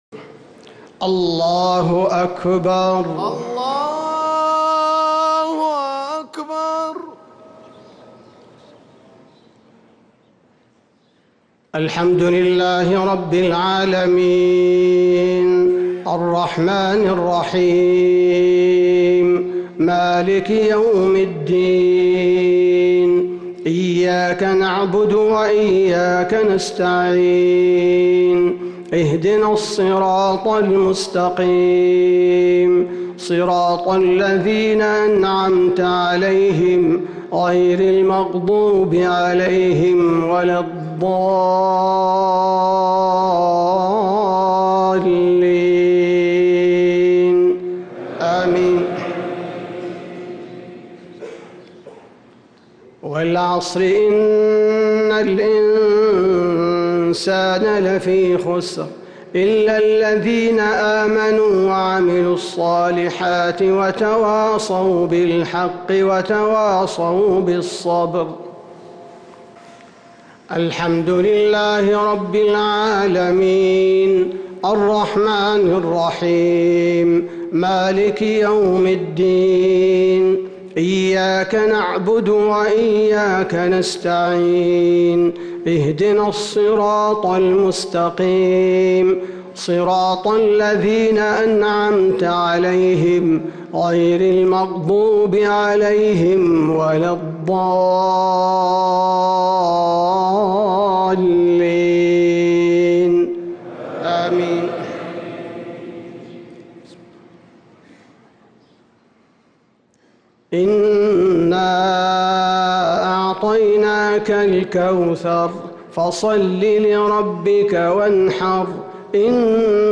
إصدار جميع تلاوات الشيخ عبدالباري الثبيتي في شهر جمادى الأولى و الآخرة 1446هـ > سلسلة الإصدارات القرآنية الشهرية للشيخ عبدالباري الثبيتي > الإصدارات الشهرية لتلاوات الحرم النبوي 🕌 ( مميز ) > المزيد - تلاوات الحرمين